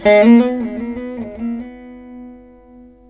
154D VEENA.wav